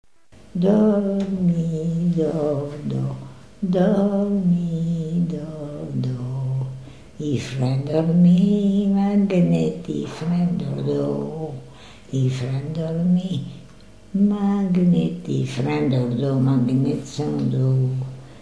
berceuse
Enquête Arexcpo en Vendée-Pays Sud-Vendée
Pièce musicale inédite